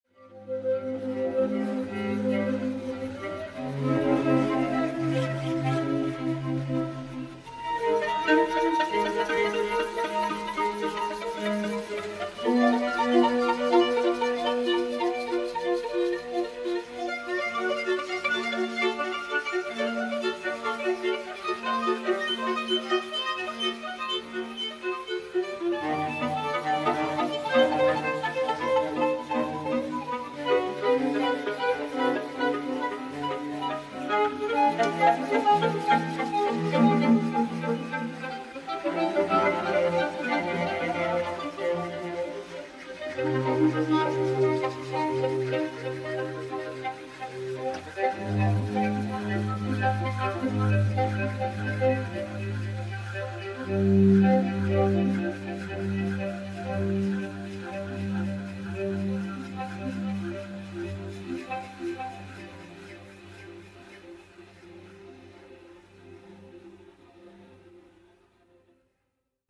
Jazz Music and More